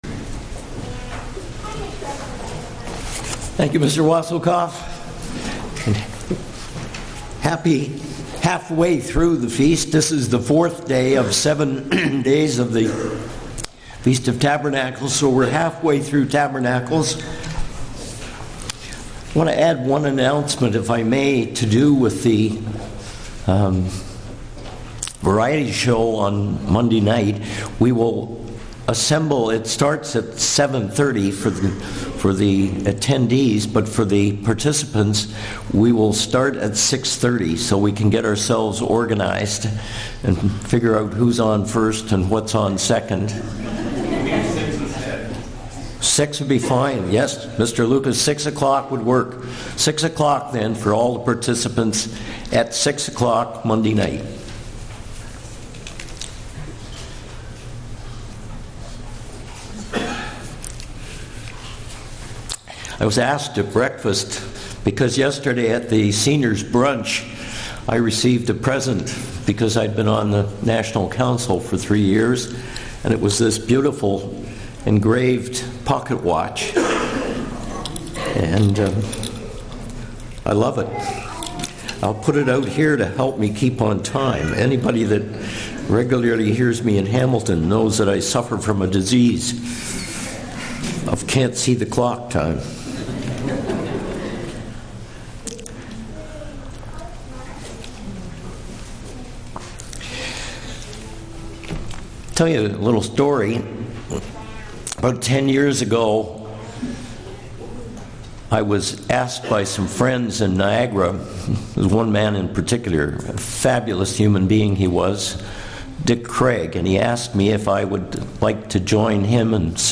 Feast of Tabernacles Sermon fear God Studying the bible?